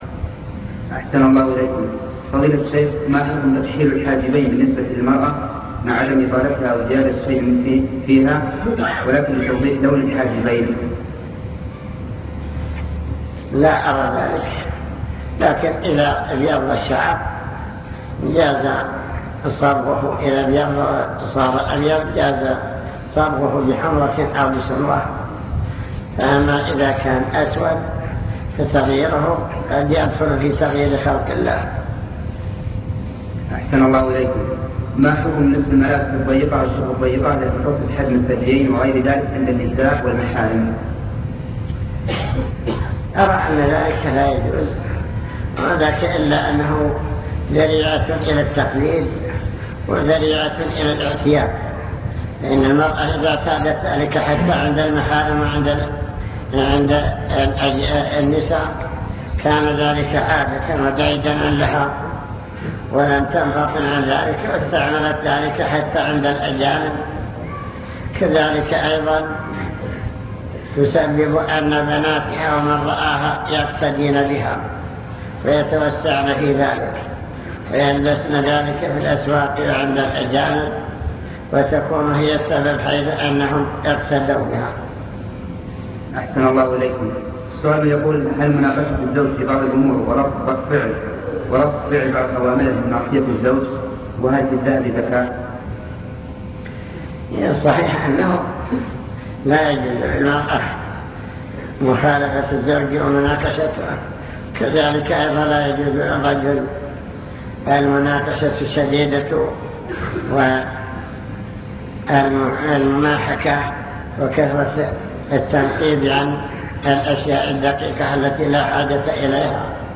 المكتبة الصوتية  تسجيلات - محاضرات ودروس  محاضرة في الجلاجل